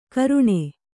♪ karuṇe